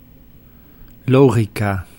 Ääntäminen
Ääntäminen Tuntematon aksentti: IPA: /lɔˈgiːk/ Lyhenteet ja supistumat (leksikografia) log.